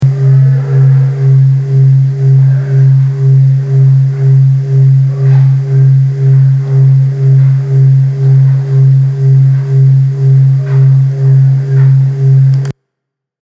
It’s introduction in the App Store reports, “Advanced Brainwave Entrainment is used to synchronize your brainwaves to deeply relaxing low-frequency alpha, theta and delta waves to help provide headache relief through deep relaxation. “
I just propped up a mic next to the Bluetooth speaker I had paired to my iPad and used GarageBand to record the audible sound.
brainwave entrainment.
brainwave-entrainment.mp3